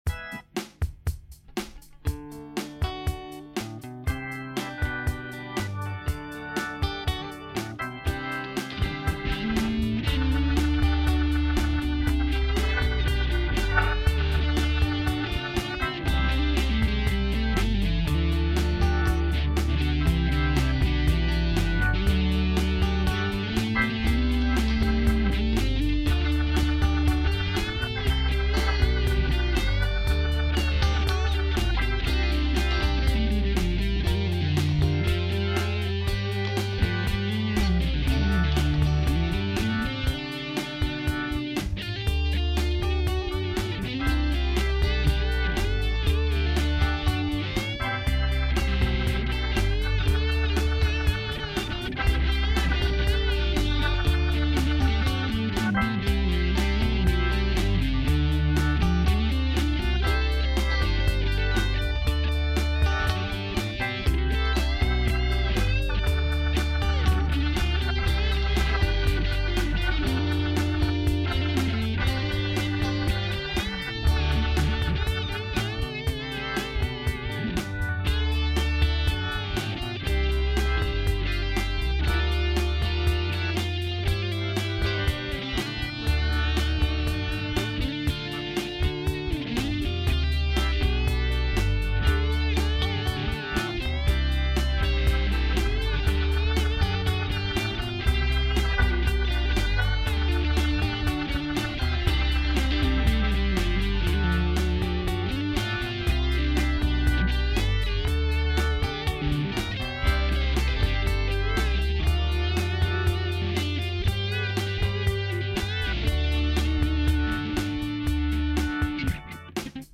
-4 pistes utilisées +BAR
-strato fender US -bass télé US -B3 hammond &leslie 760
-micro shure SM57 sur les horns -guitare et basse direct sur zoom 1608 CD